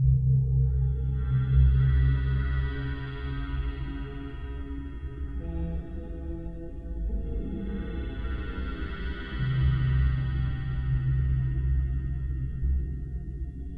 Ambience3.wav